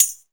TAMB#2   MPC.wav